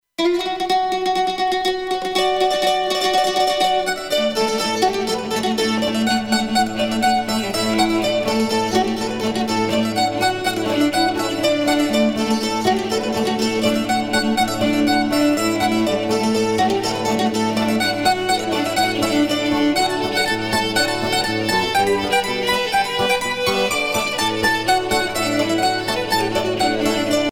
danse : reel
Pièce musicale éditée